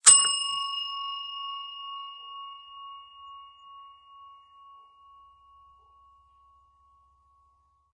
ding.ogg